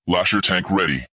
I optimized the streamlined configuration of the previously produced Lasher Tank and equipped it with a new Lasher Tank voice, which is generally consistent with the tone of the original dialogue.
Voicelines sound a little bit AI, but good.